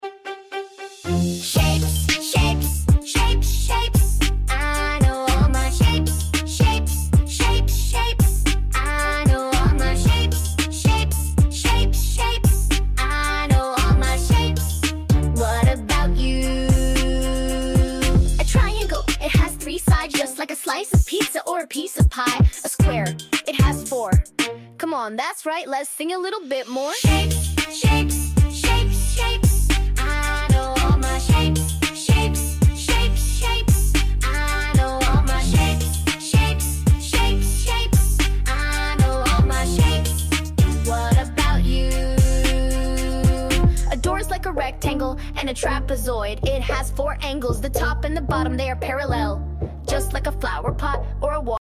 This video is a fun way to learn shapes with the “Shapes, Shapes” song. Dance along to the shape song while also learning the shapes and where your child can identify these shapes in everyday life.